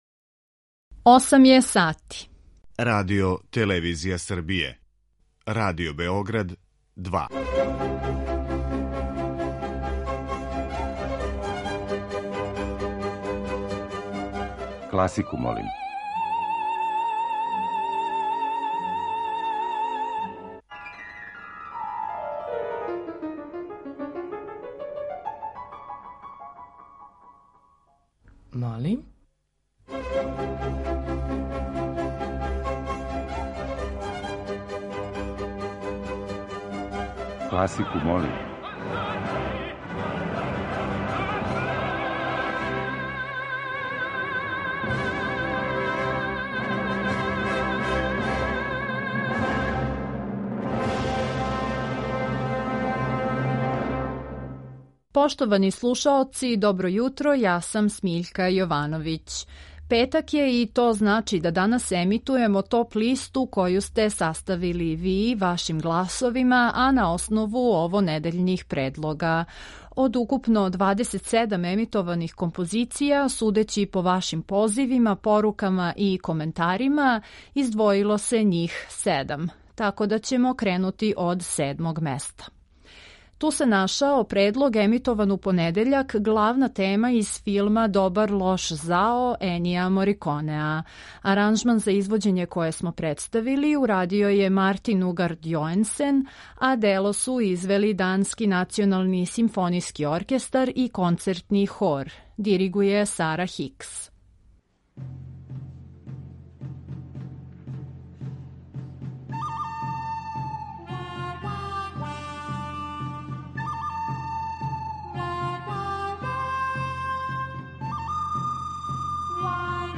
И ове седмице, од понедељка до четвртка емитоваћемо разноврсне предлоге композиција класичне музике.